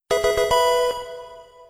success_small.wav